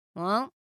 gold ship huh Meme Sound Effect
gold ship huh.mp3